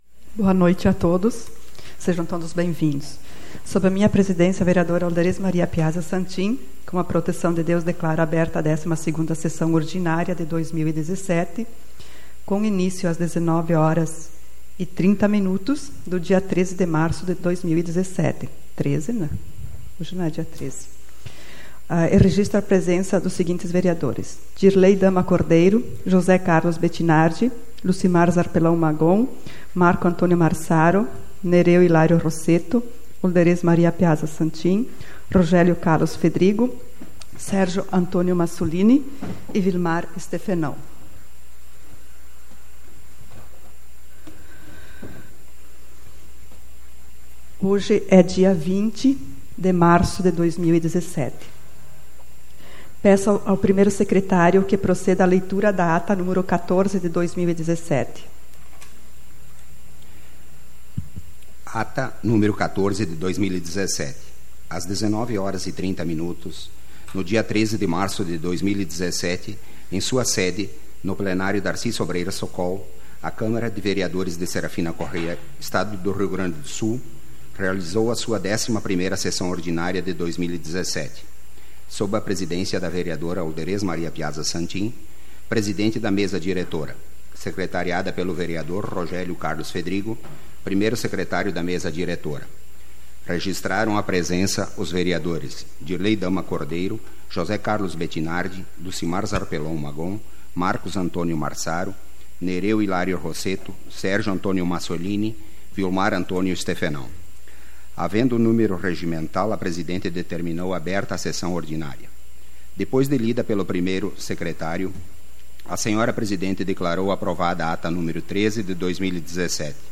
SAPL - Câmara de Vereadores de Serafina Corrêa - RS